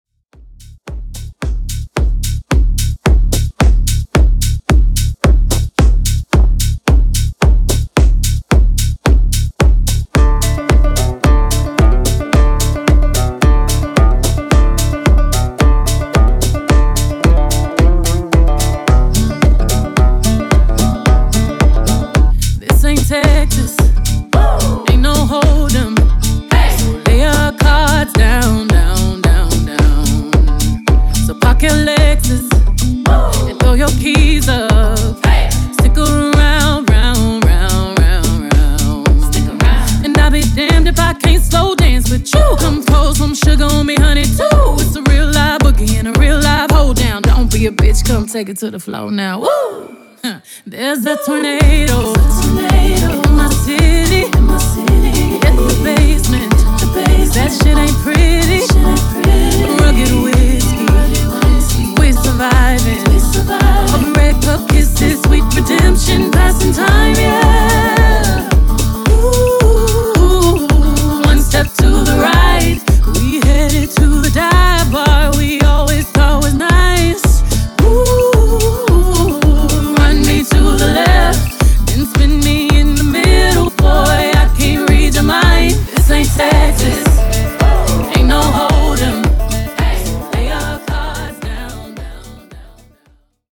Genres: COUNTRY , RE-DRUM , TOP40
Clean BPM: 110 Time